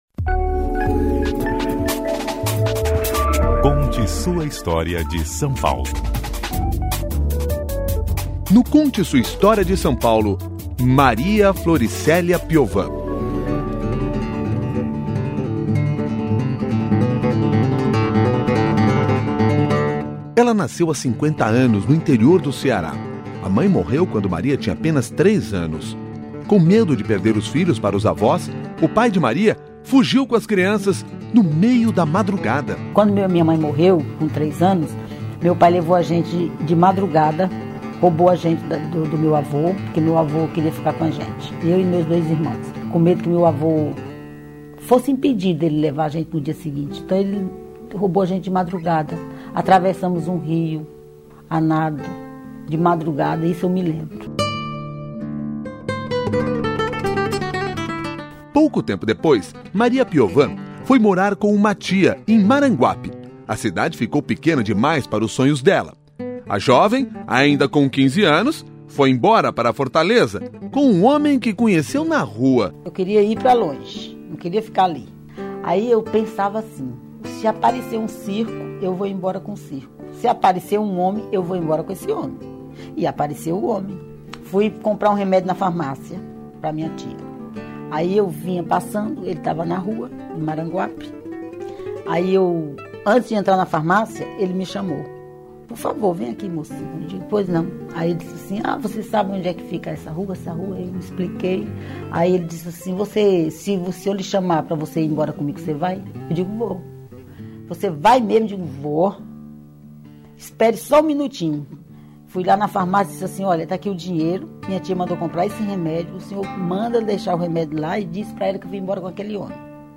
O Conte Sua História de São Paulo vai ao ar aos sábados, logo após as dez e meia da manhã, no CBN São Paulo.